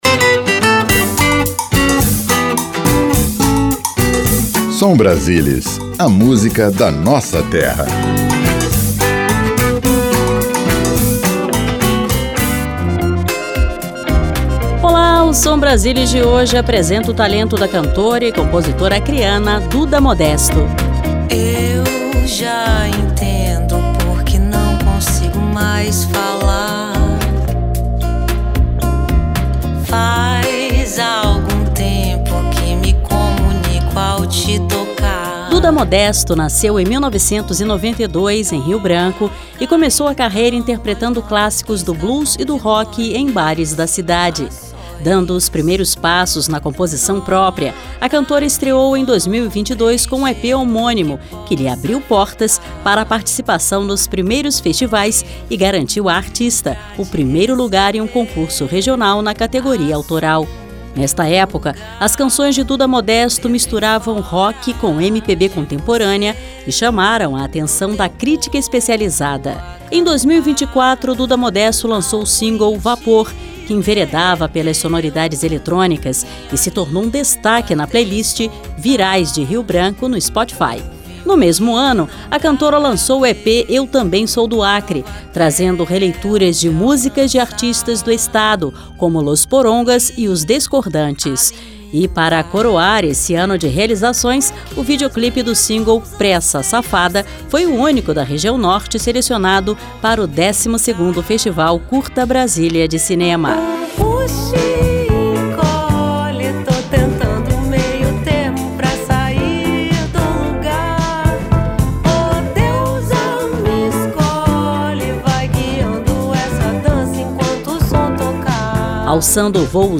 Música